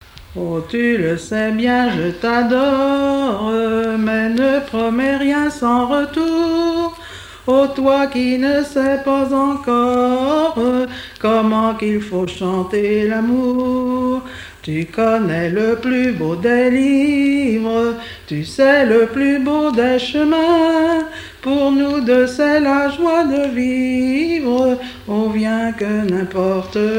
danse : valse
Genre strophique
Pièce musicale inédite